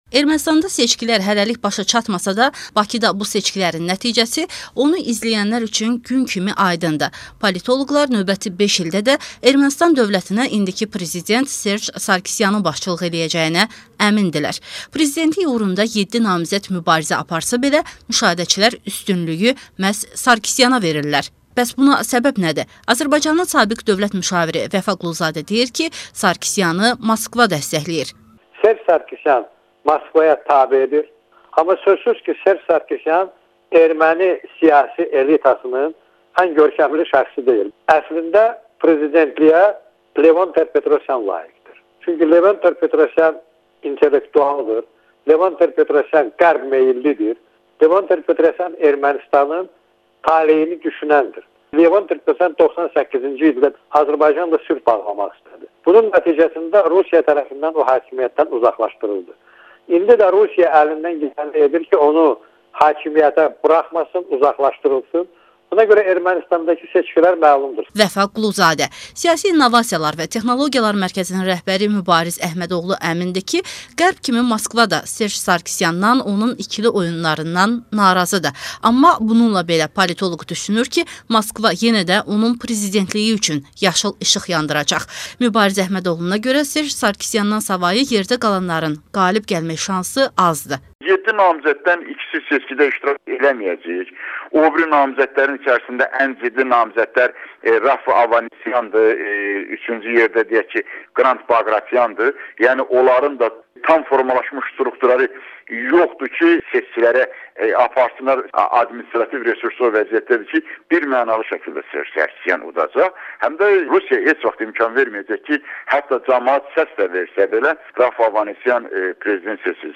Kontekst proqramında Azərbaycan politoloqları Ermənsitandakı prezident seçkilərini şərh edirlər